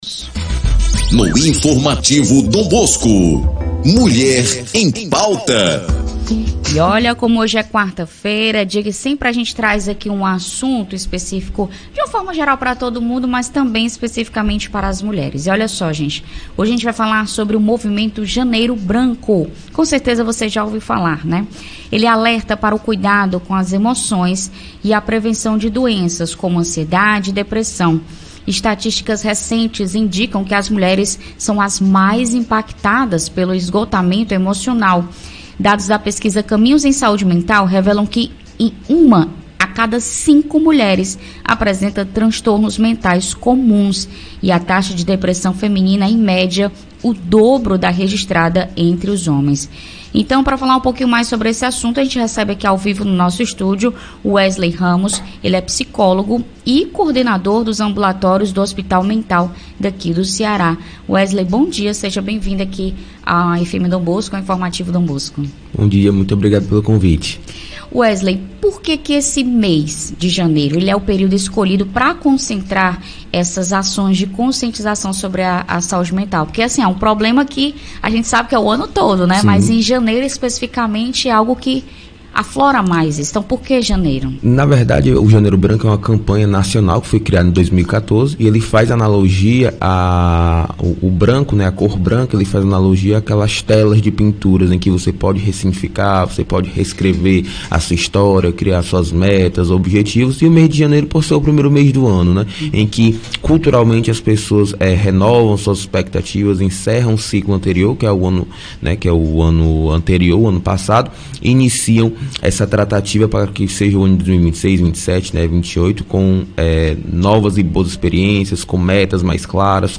ENTREVISTA-1401.mp3